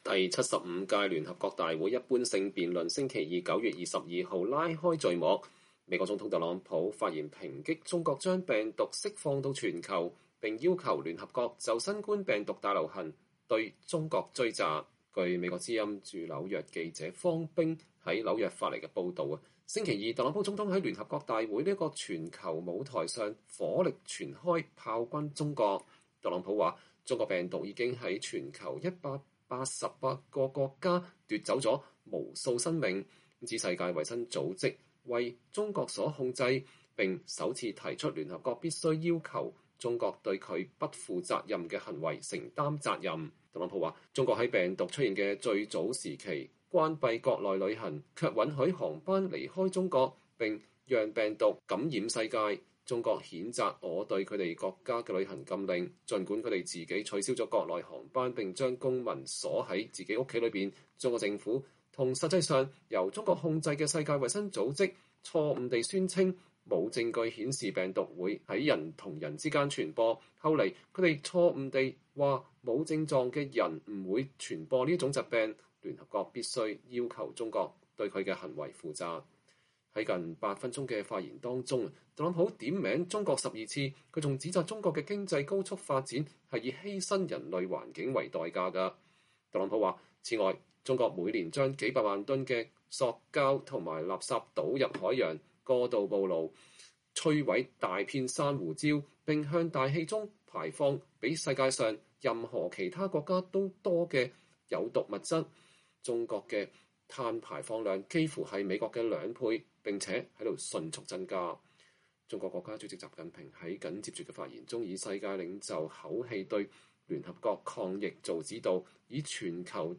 美國總統特朗普在第75屆聯合國大會上發表講話。